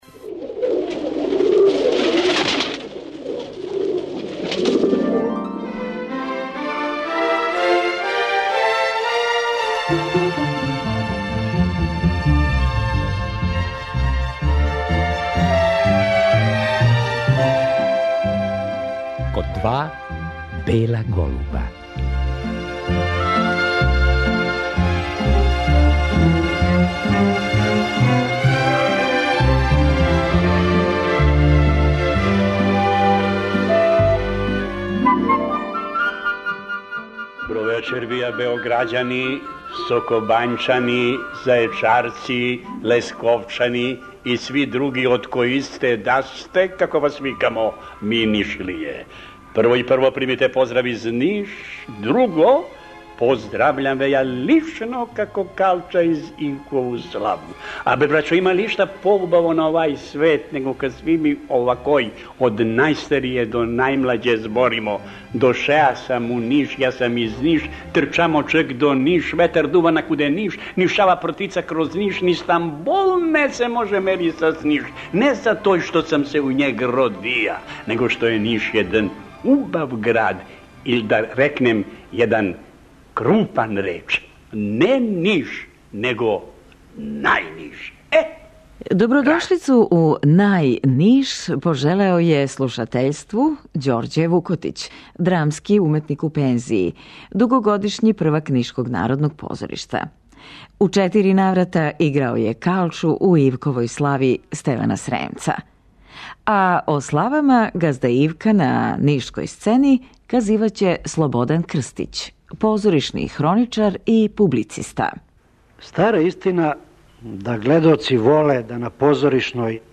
Пре тридесетак година, делови "Ивкове славе" записани су на грамофонској плочи.
Захваљујући Музеју града Ниша, слушаоци су у прилици да чују ондашње глумце, свираче и певаче.